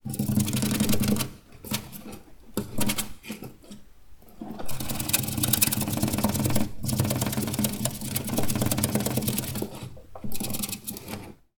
Звуки песчанки: Грызун шуршит лапками по коробке